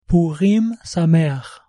purim-sameach.mp3